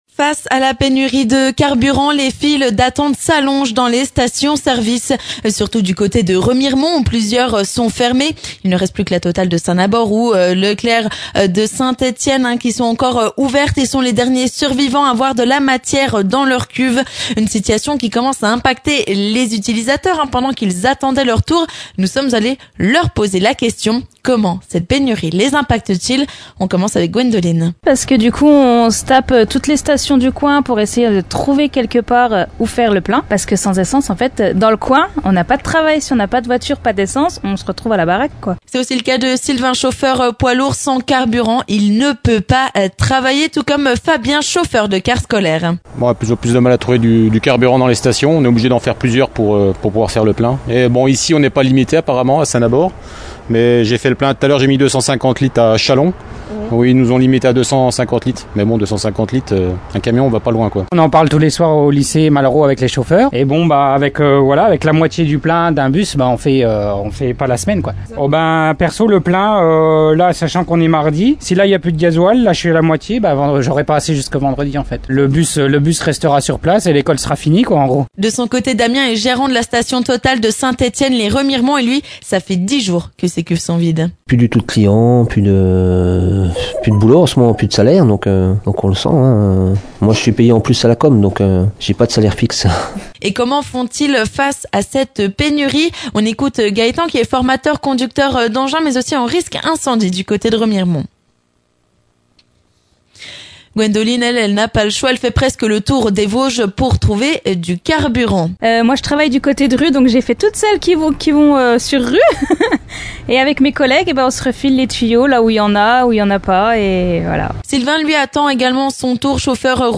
Face à la pénurie de carburant, comment les automobilistes vosgiens réagissent ? Nous sommes allés slalomer entre les voitures, les bus et les camions sur deux stationos-services à Remiremont.